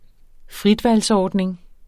Udtale [ ˈfʁidvals- ]